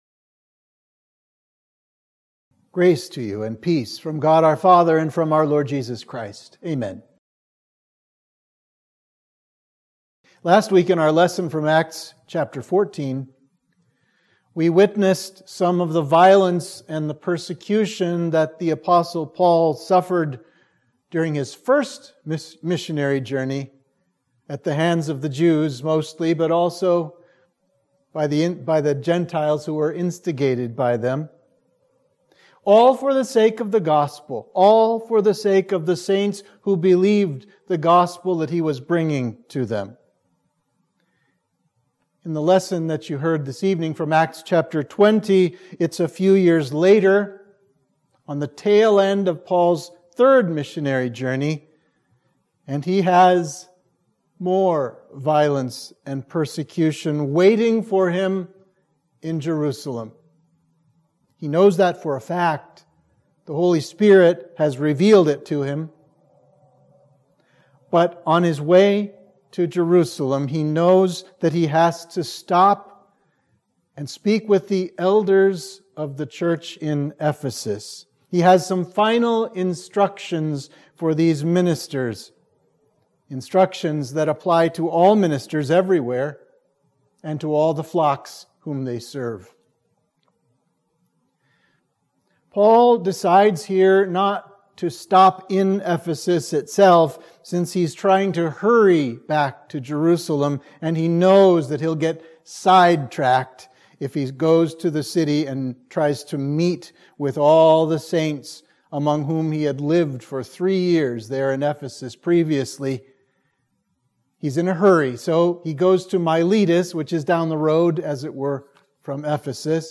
Sermon for Midweek of Trinity 8